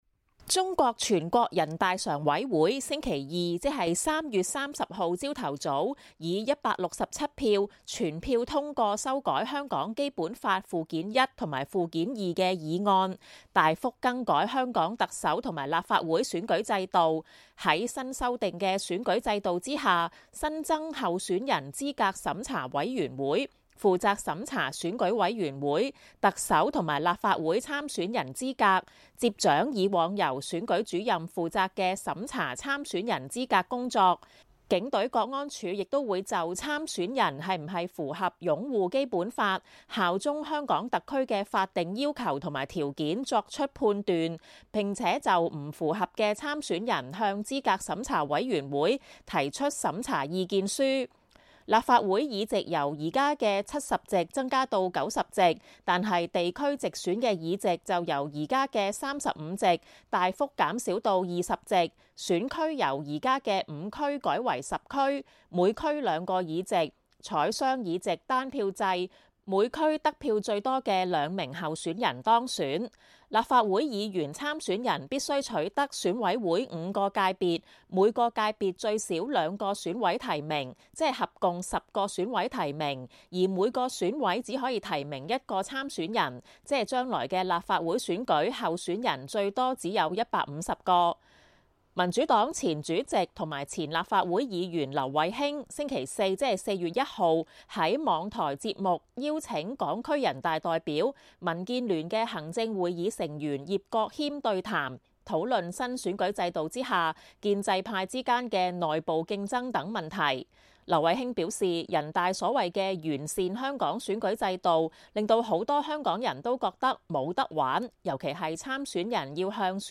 中國全國人大常委會星期二修訂香港《基本法》有關特首及立法會選舉制度，以確保“愛國者治港”，引起各界關注港人的參選空間被大幅收緊。民主黨前主席劉慧卿星期四在網台節目與港區人大代表葉國謙對談，討論新選舉制度之下，建制派之間的內部競爭等問題。